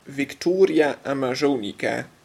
hier voor de Groningse uitspraak) in bloei.